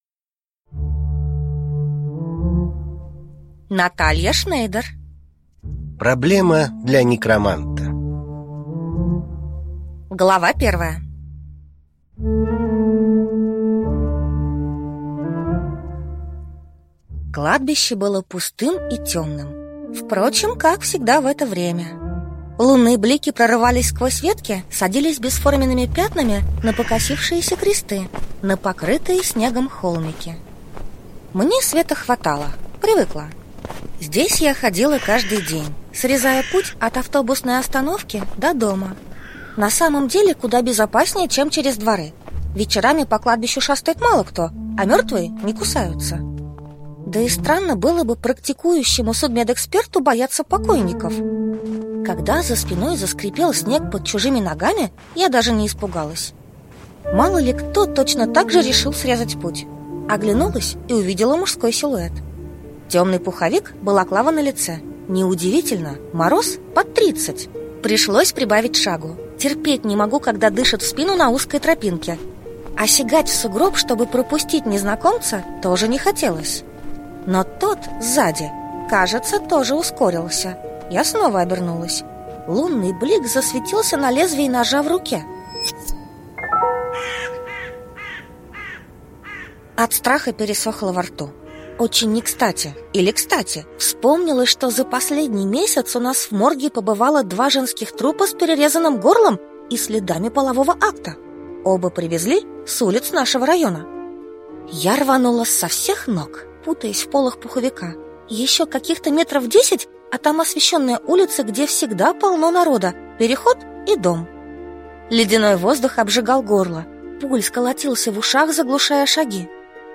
Аудиокнига Проблема для некроманта | Библиотека аудиокниг